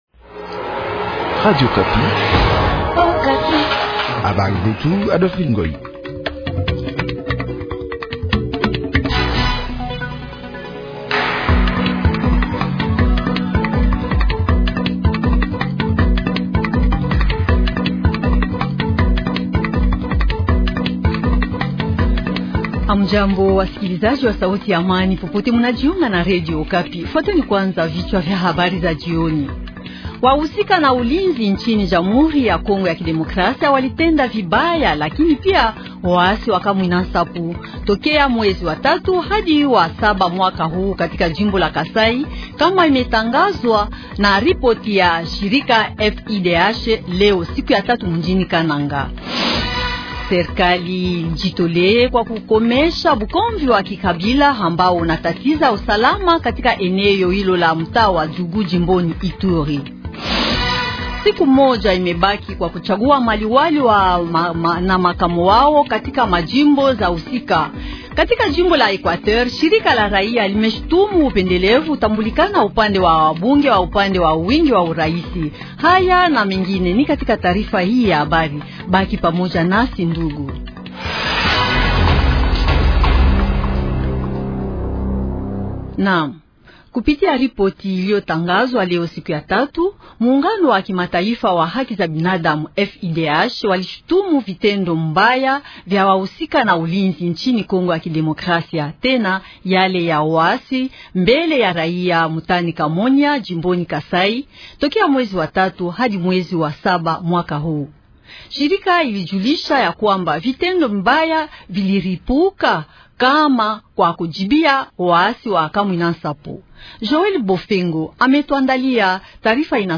Journal Swahili Soir